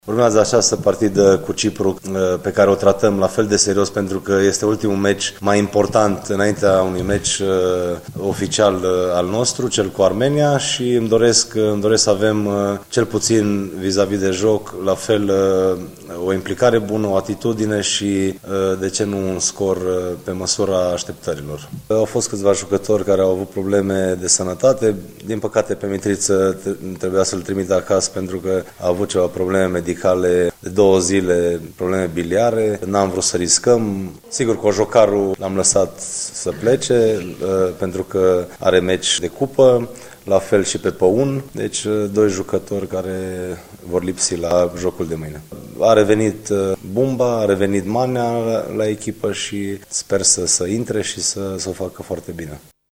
în cadrul unei conferinţe de presă